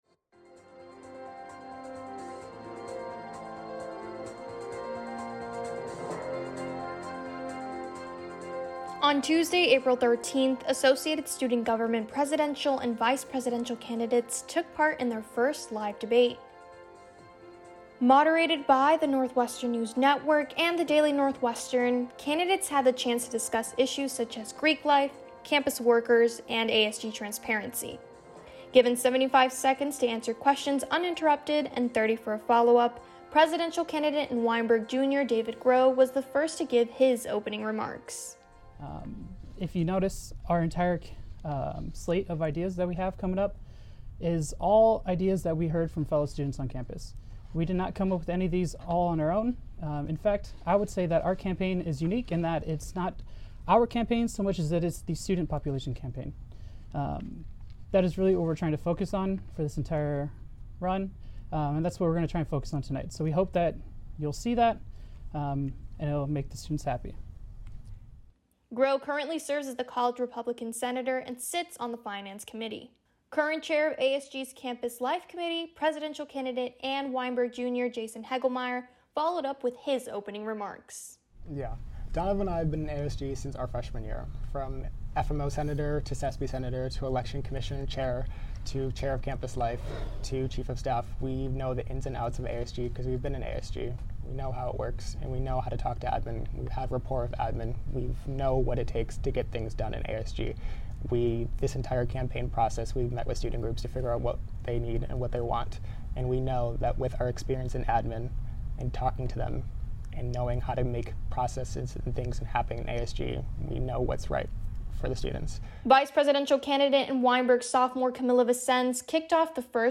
On Tuesday April 13th, Associated Student Government presidential and vice presidential candidates took part in their first live debate.